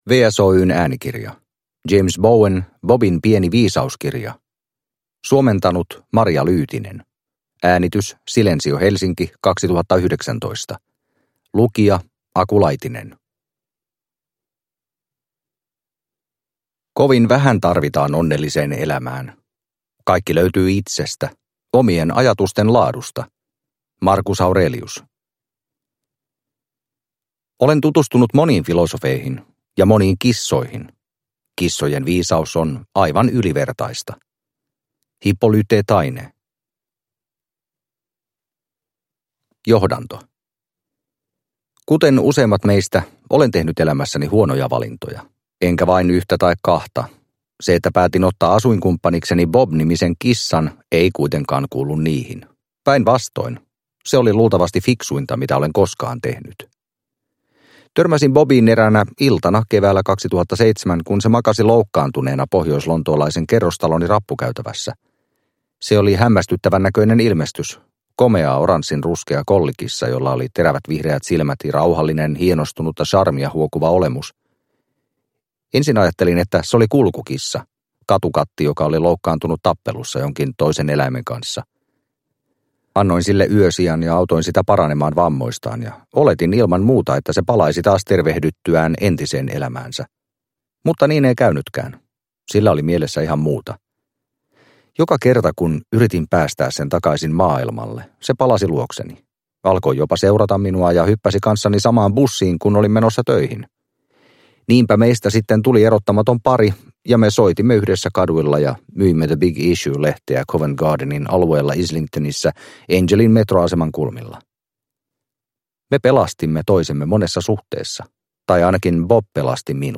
Bobin pieni viisauskirja – Ljudbok – Laddas ner